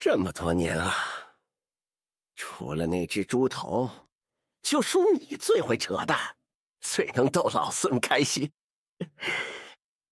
剪除静音前